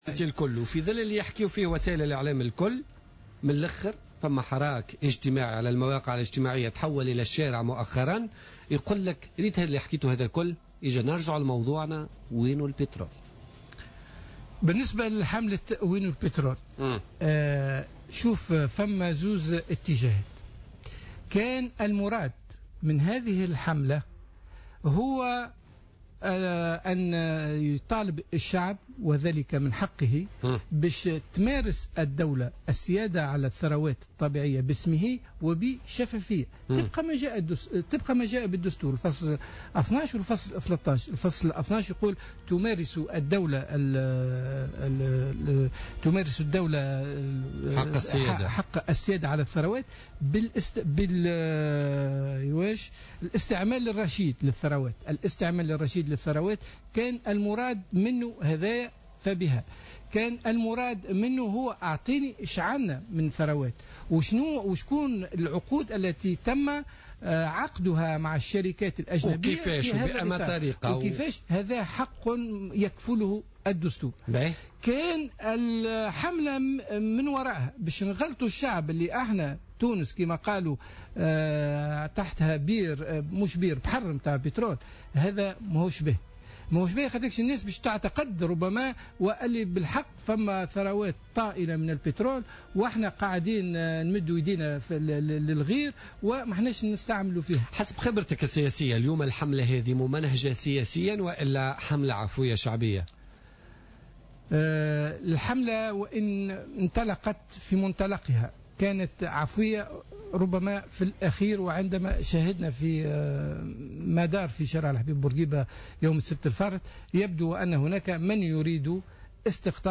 أكد النائب السابق بالمجلس الوطني التأسيسي محمد قحبيش ضيف بوليتيكا اليوم الخميس 4 جوان 2015 أن حملة "وين البترول " بدأت عفوية ثم تم تحويل وجهتها وتسيسيها من أجل ارباك الحكومة ووضع حد لها وإسقاطها وفق قوله.